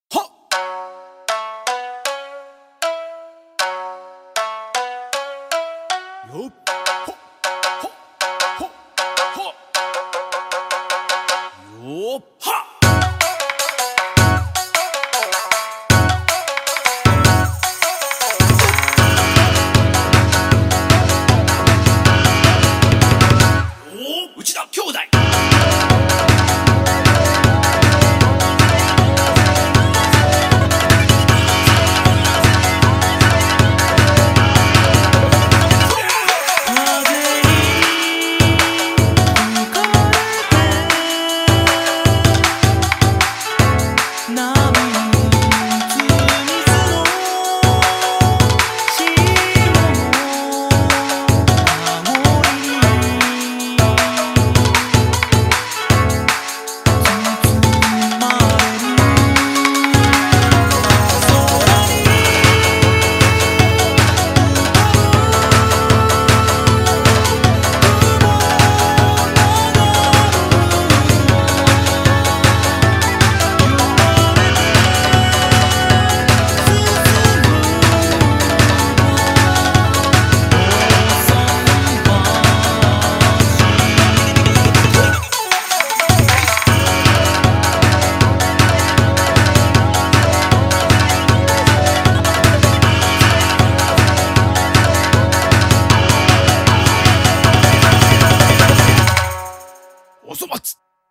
BPM156
Audio QualityPerfect (High Quality)
A shamisen remix